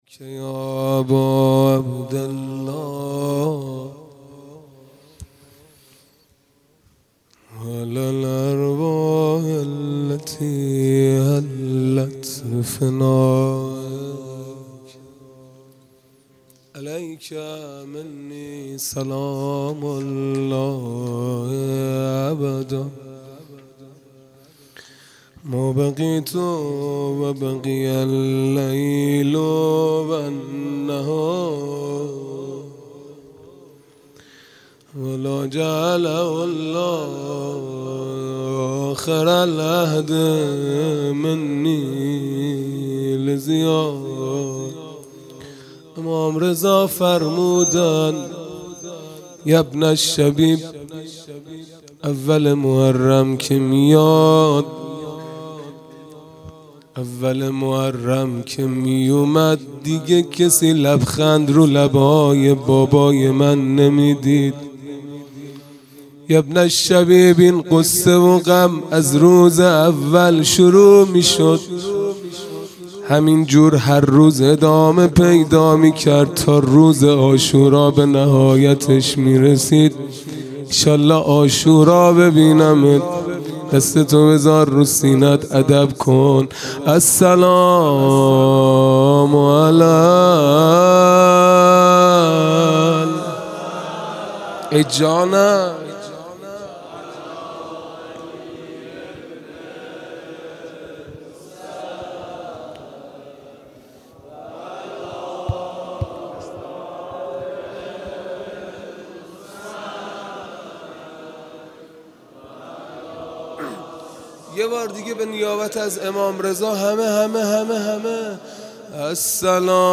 خیمه گاه - هیئت بچه های فاطمه (س) - روضه | سید و سالار بی همتا